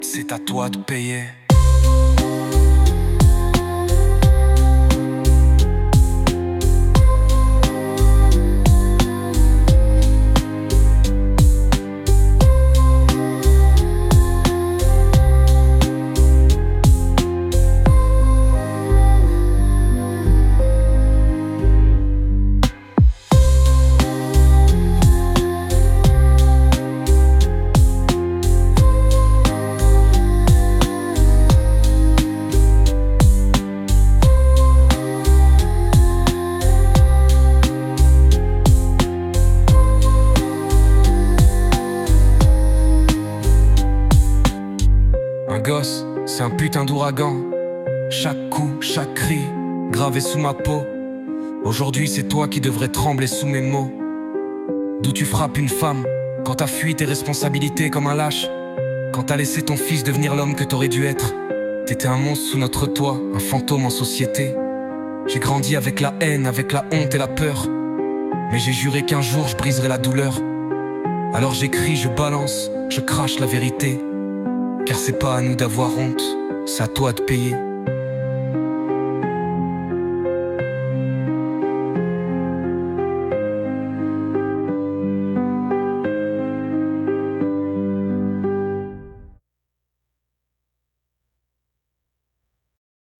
Alternative 1 : Chœurs « lalala » et voix parlée